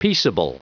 Prononciation du mot peaceable en anglais (fichier audio)
Prononciation du mot : peaceable